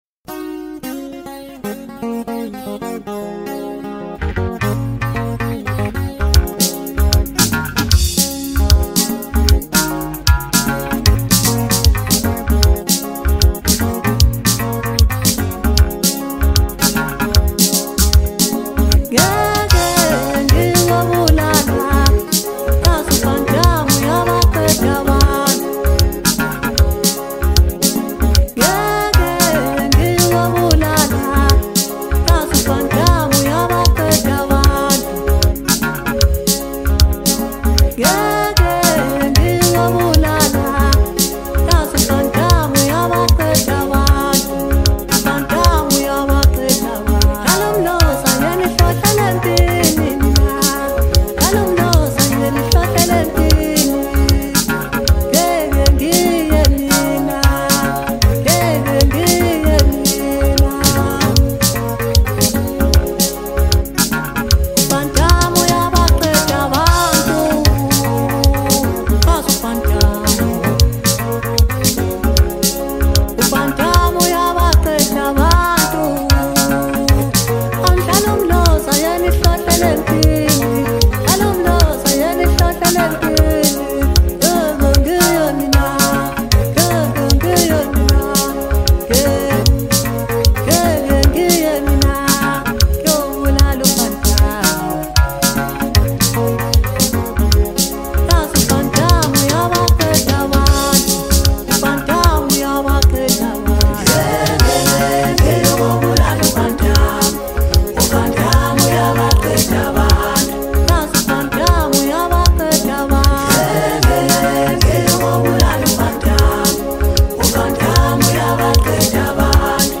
Home » Hip Hop » Hottest » Maskandi